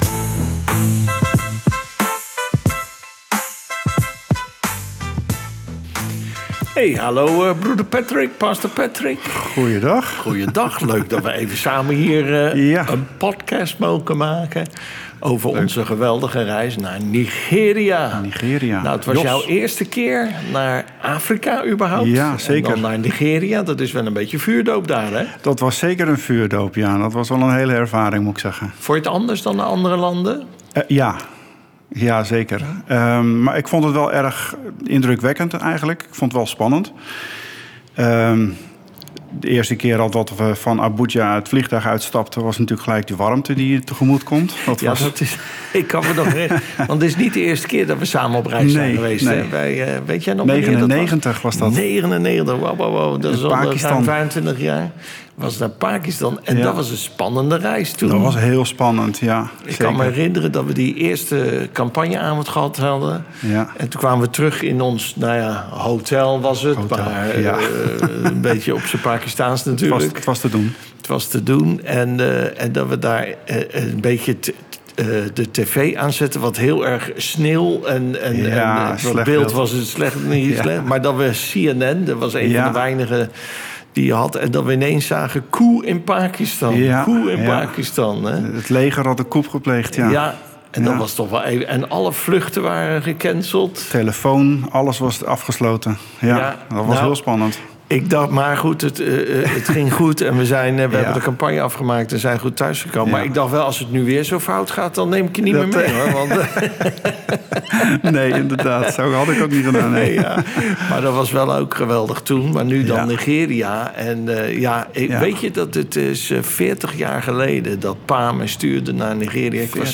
In deze podcast delen zij hun avonturen, verhalen en indrukwekkende getuigenissen van de wonderen die God deed.